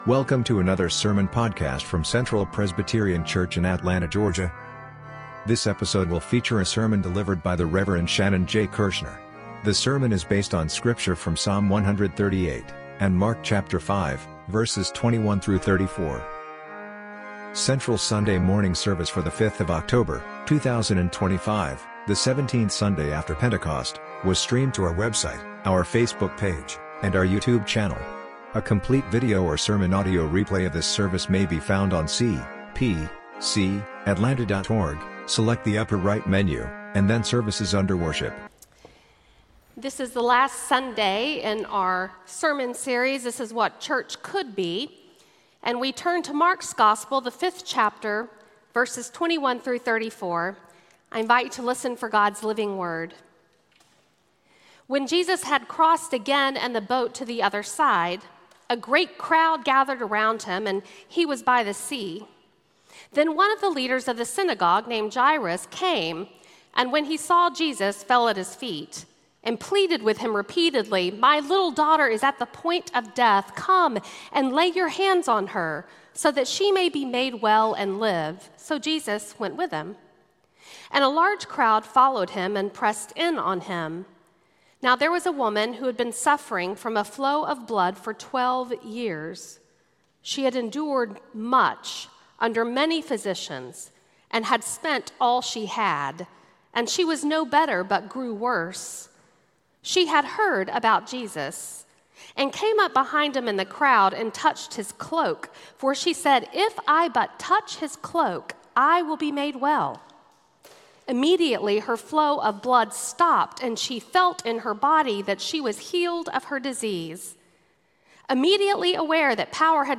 Sermon Audio:
Series: This is What Church Should Be Passage: Psalm 130, Mark 5:21-34 Service Type: Sunday Sermon